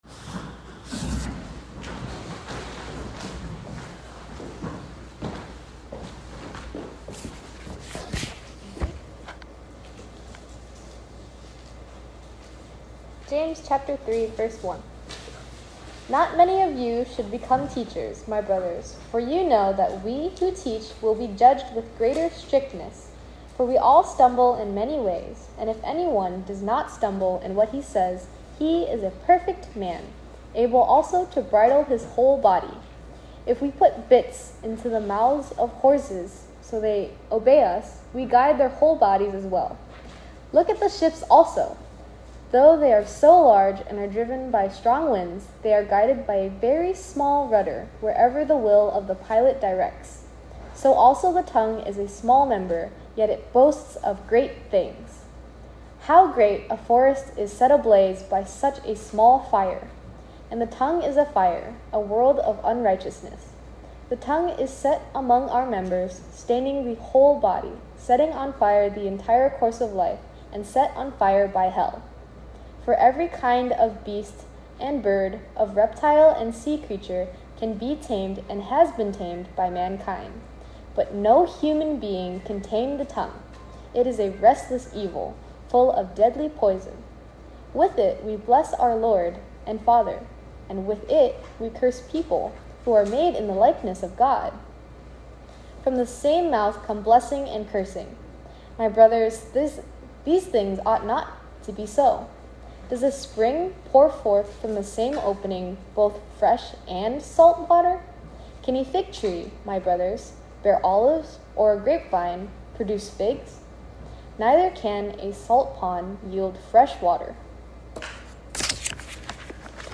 James 3:1-12 Sermon “The TONGUE”